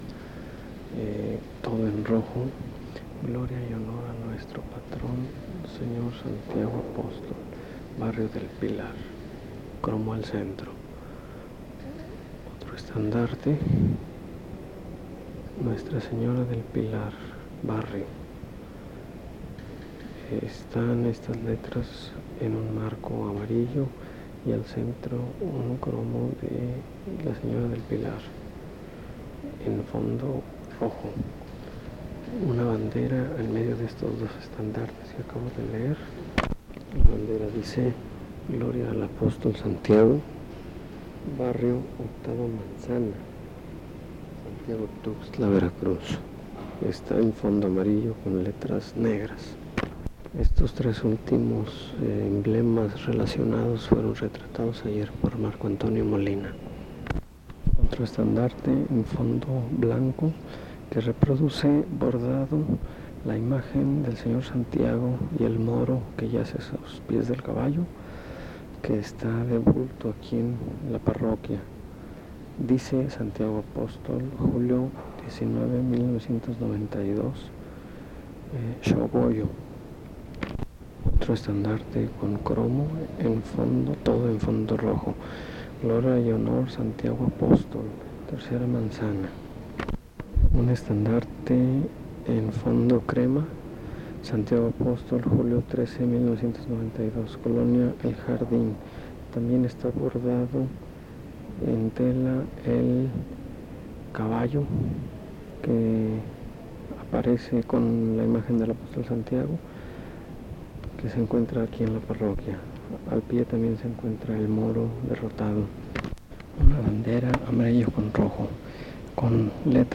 01 Músicos
Peregrinación de Santiago Apostol